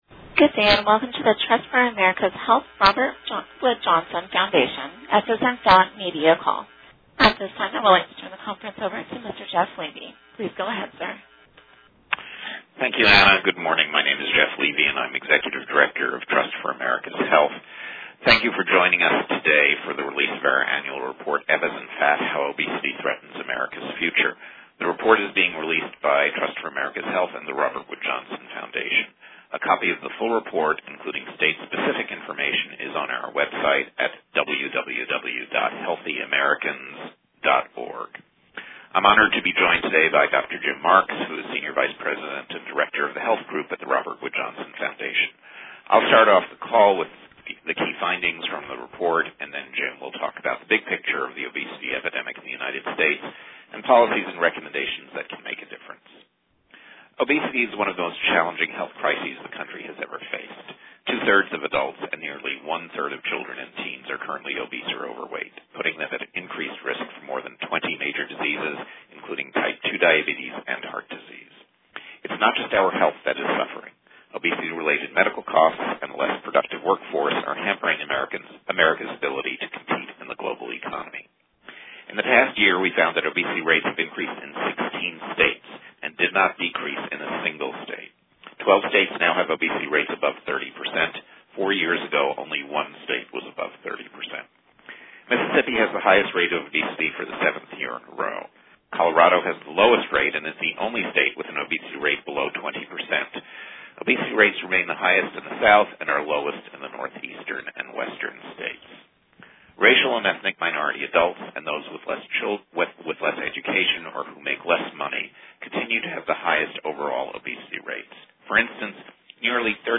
Resources Full Report (2011) TFAH Release Audio of the News Conference Discover State Releases Explore state-level releases on this report by choosing from the drop-down menu below.